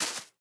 Minecraft Version Minecraft Version latest Latest Release | Latest Snapshot latest / assets / minecraft / sounds / block / cherry_leaves / break5.ogg Compare With Compare With Latest Release | Latest Snapshot